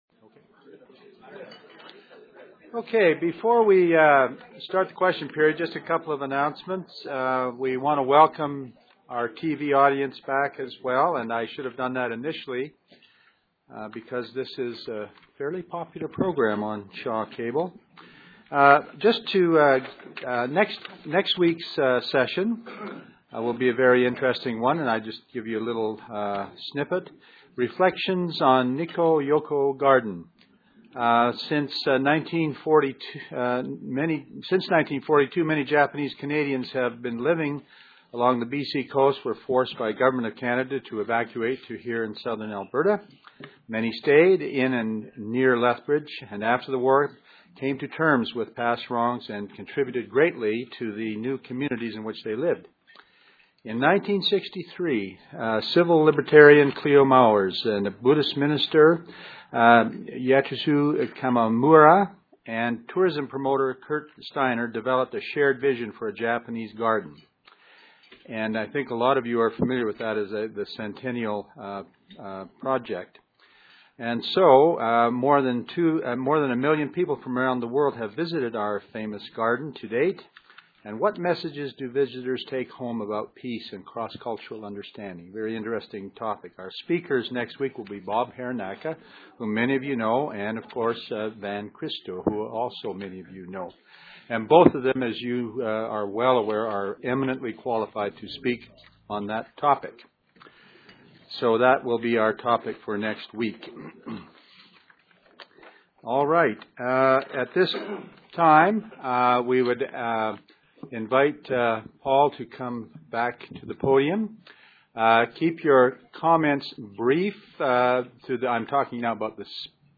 Philosophers of War have had much to say about this conundrum. In this brief talk, I''ll be rehearsing some of what they''ve had to say.